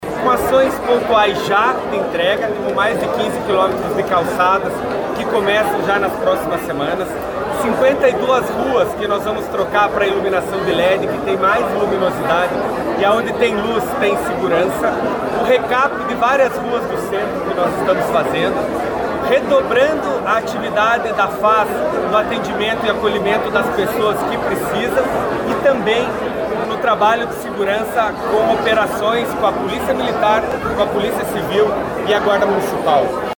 O prefeito Eduardo Pimentel falou sobre a implementação de ações de curto prazo.